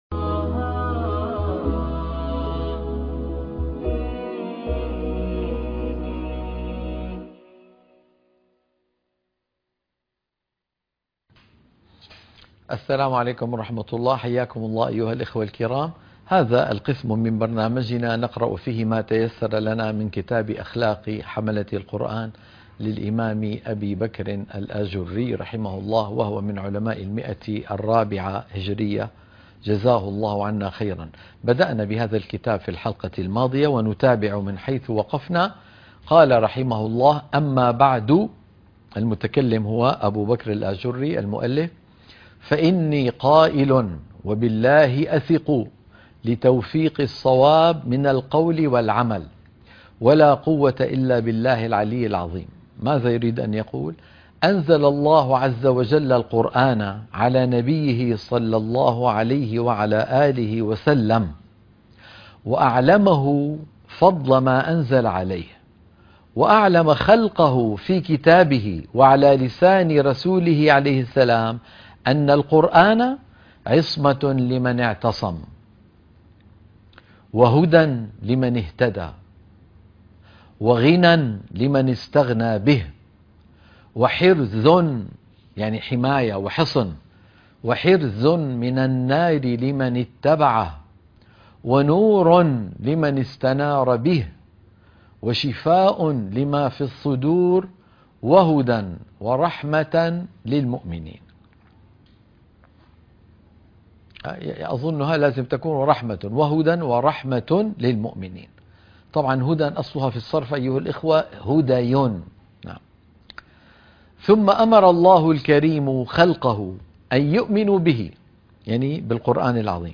قراءة كتاب أخلاق حملة القرآن _ الحلقة الثانية